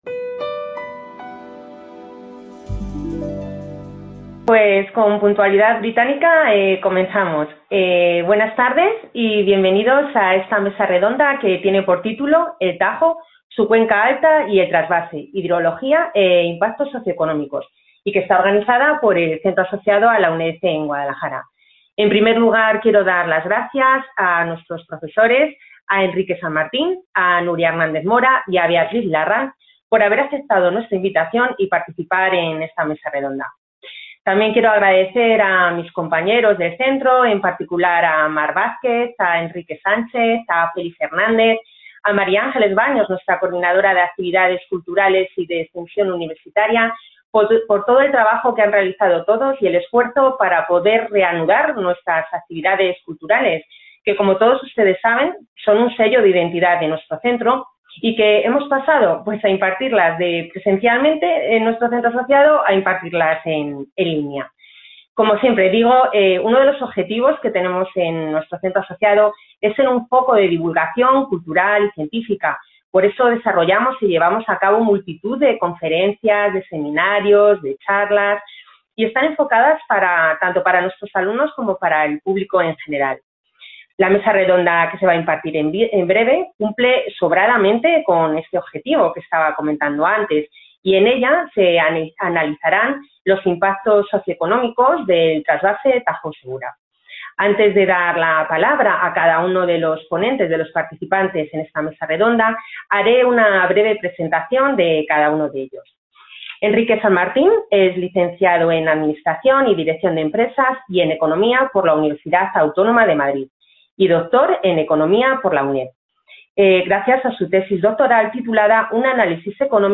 MESA REDONDA.